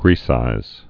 (grēsīz)